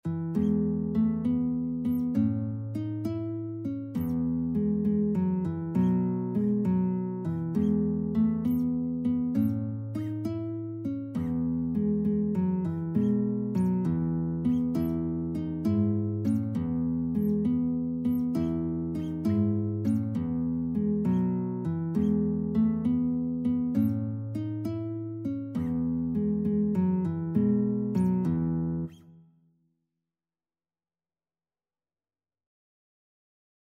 Christian
6/8 (View more 6/8 Music)
E3-E5
Guitar  (View more Easy Guitar Music)
Classical (View more Classical Guitar Music)